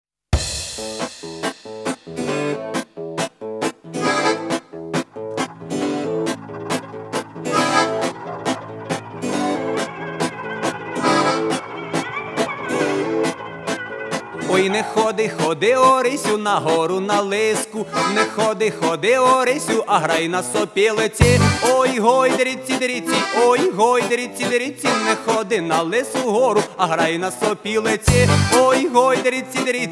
Жанр: Фолк-рок / Рок / Украинские